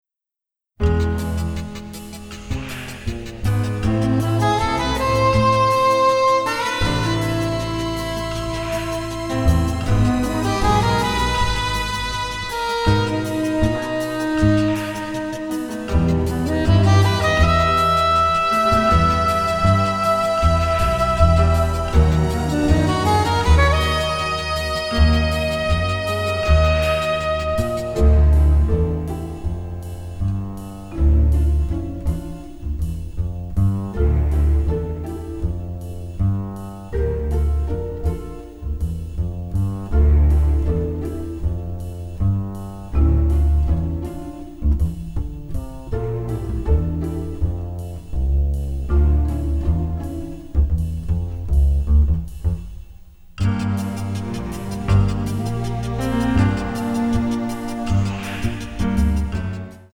romantic noir score